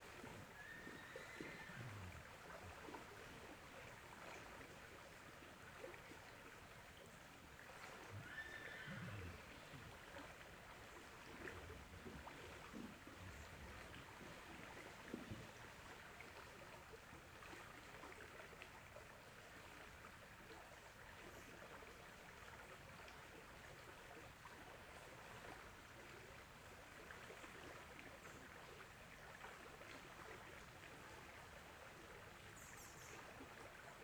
Rio perto da queda dagua com cavalo Água , Animais , Cavalo , Queda-d'água , Rio Cavalcante Stereo
CSC-01-010-LE - Rio perto da queda dagua com cavalo.wav